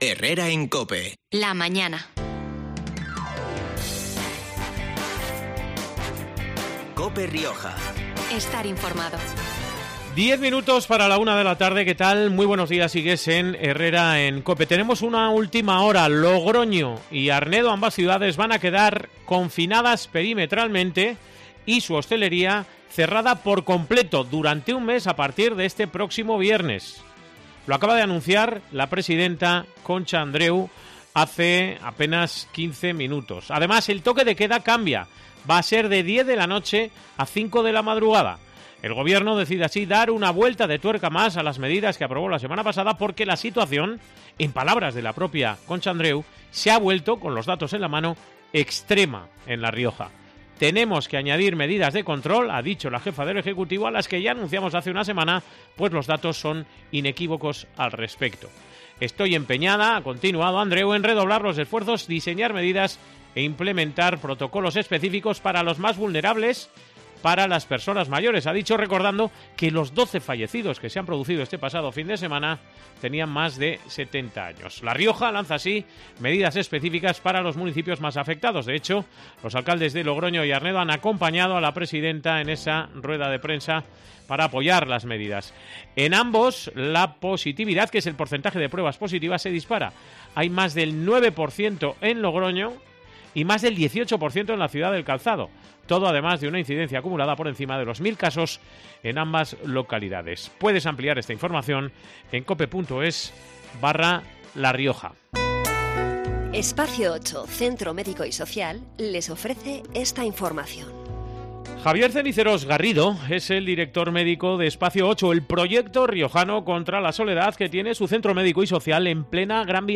llamada de COPE Rioja